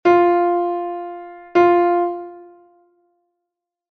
Branca con puntiño igual a branca e negra ligadas; negra con puntiño igual a negra e corchea ligadas